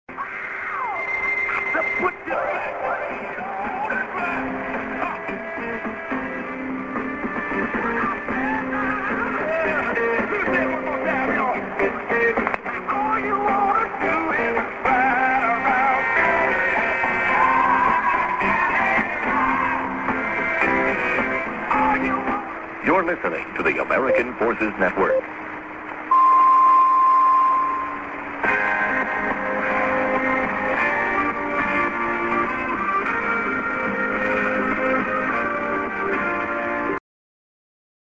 00'22":ID(man:Your Listening to American Forces Network)->TS->music //1575